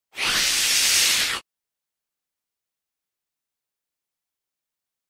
vampire_hiss.mp3